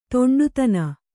♪ to'ṇḍutana